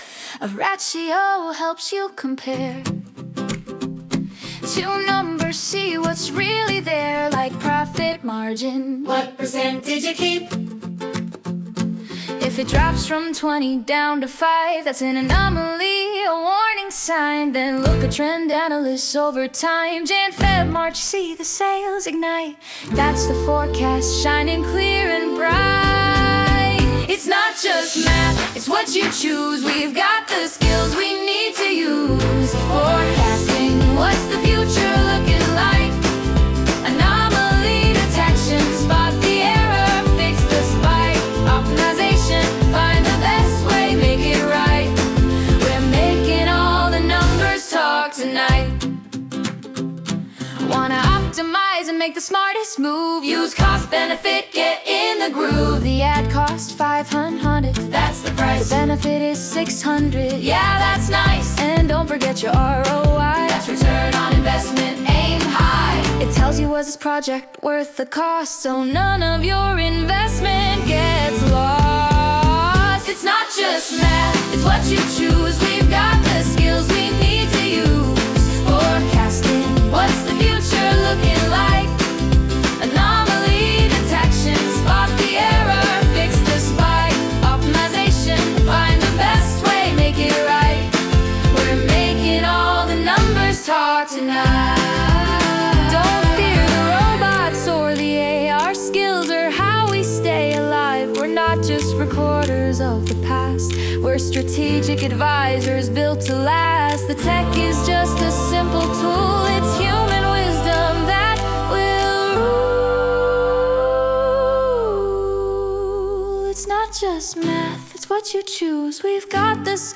The Sing Along Experience